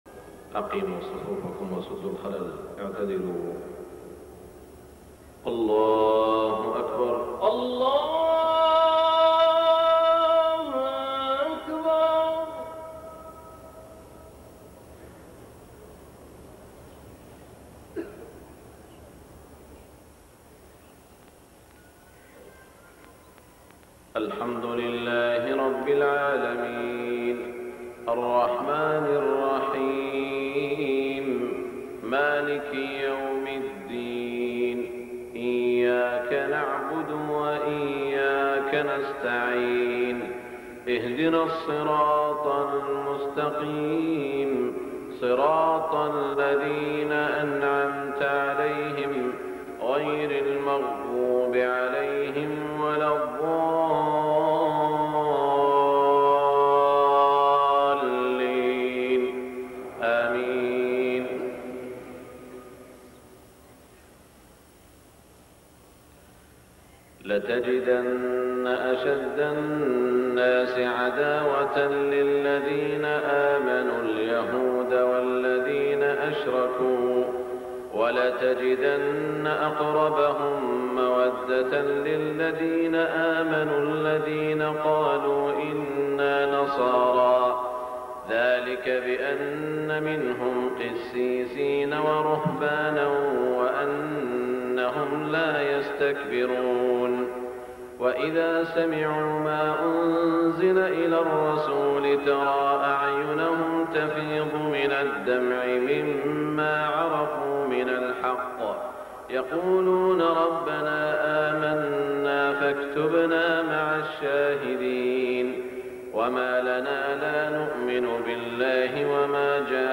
صلاة الفجر 1424هـ من سورة المائدة > 1424 🕋 > الفروض - تلاوات الحرمين